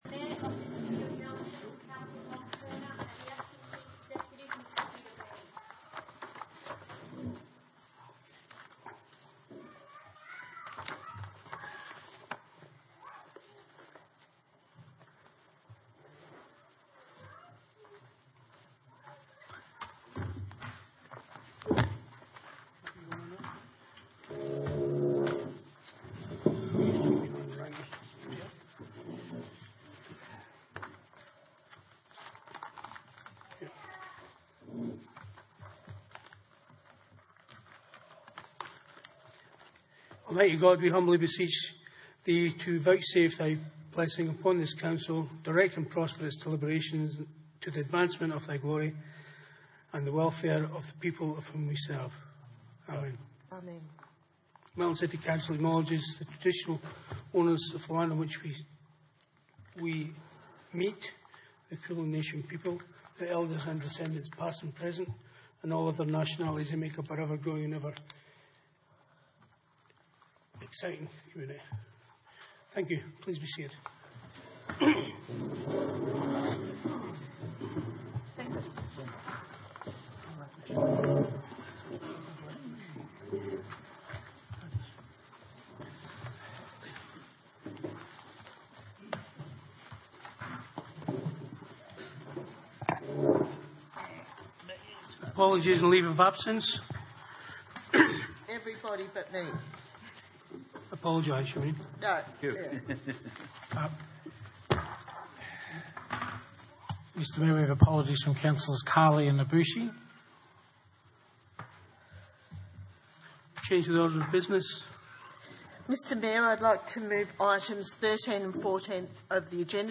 Ordinary Meeting 27 May 2019
Burnside Community Hall, 23 Lexington Drive, Burnside, 3023 View Map